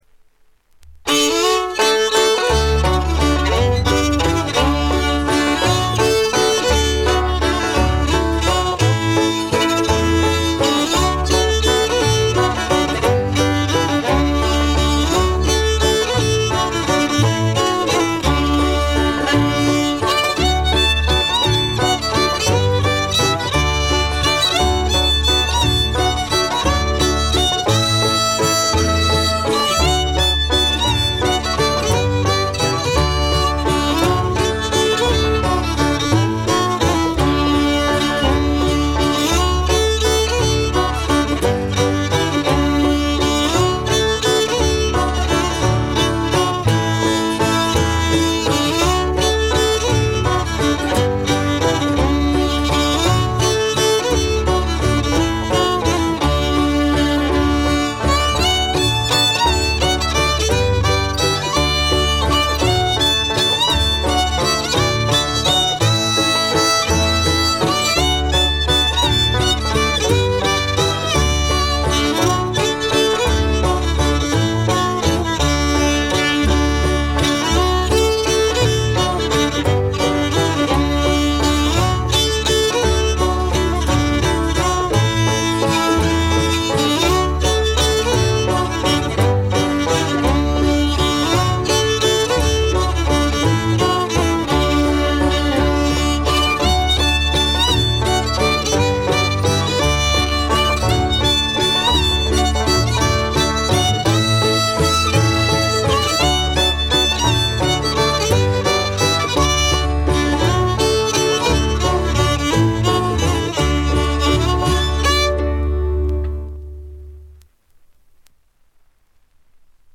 ホーム > レコード：カントリーロック
親子による枯れた演奏が味わい深くマニアの琴線を揺さぶります。
試聴曲は現品からの取り込み音源です。
Banjo, Guitar, Mandolin, Bass, Fiddle